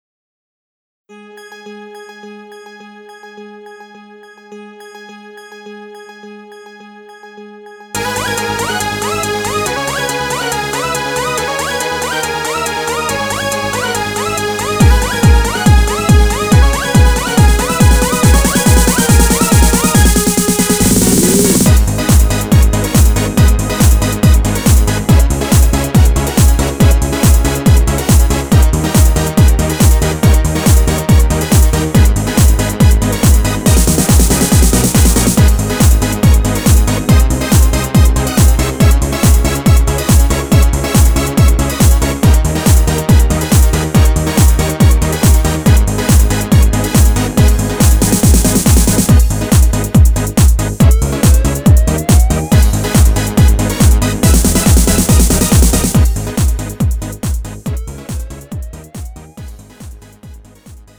MR 반주입니다.
Lite MR은 저렴한 가격에 간단한 연습이나 취미용으로 활용할 수 있는 가벼운 반주입니다.